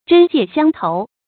針芥相投 注音： ㄓㄣ ㄐㄧㄝ ˋ ㄒㄧㄤ ㄊㄡˊ 讀音讀法： 意思解釋： 《三國志·吳志·虞翻傳》「虞翻字仲翔，會稽余姚人也」裴松之注引三國吳韋昭《吳書》：「虎魄不取腐芥，磁石不受曲針。」